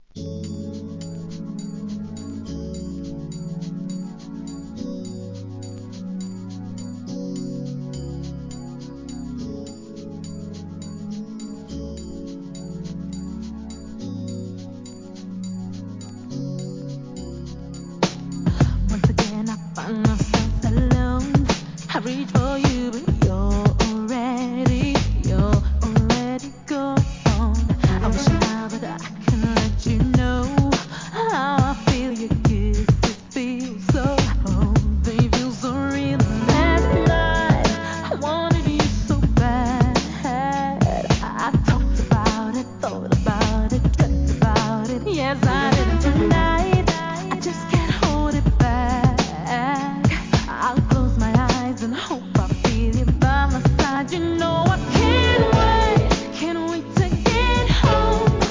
HIP HOP/R&B
GOOD UK R&B!!